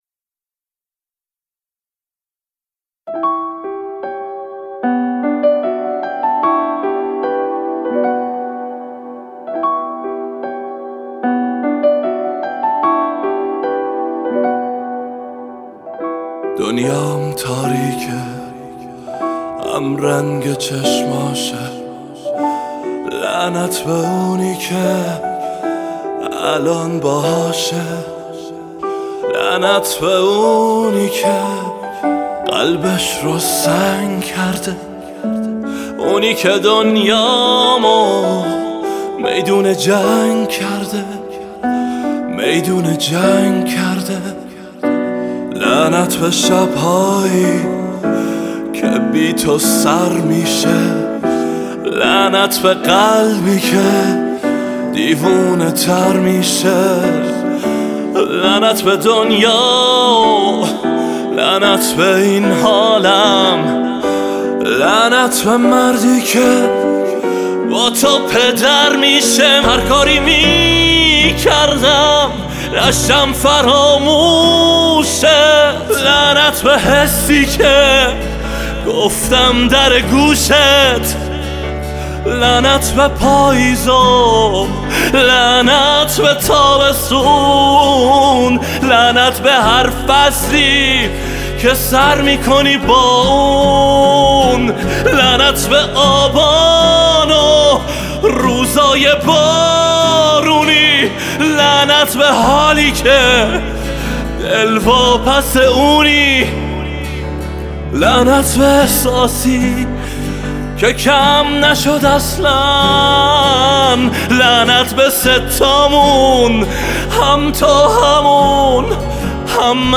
سبک : , راک
حس و حال : عاشقانه